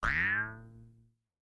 Sound effect for when a Hefty Goonie jumps in Yoshi's New Island.
Twang_Jews_Harp_2.oga.mp3